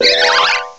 cry_not_roserade.aif